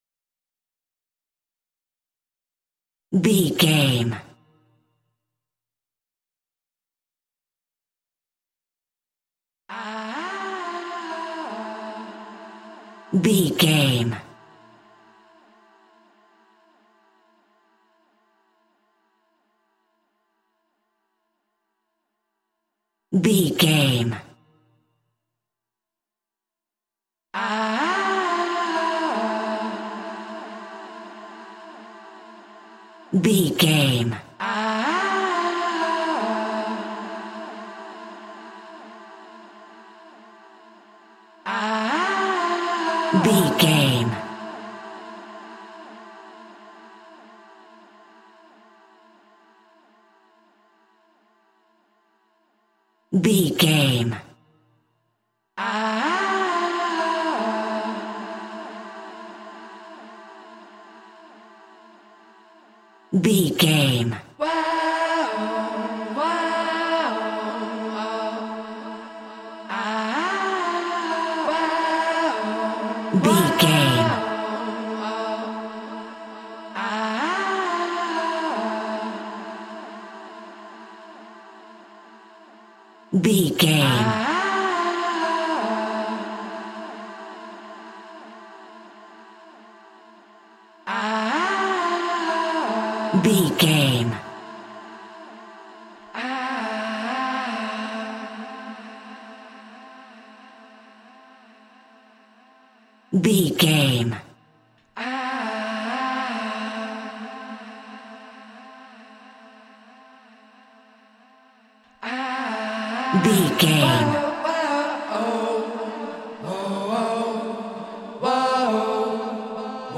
Fast paced
Aeolian/Minor
driving
energetic
synth pop
alternative rock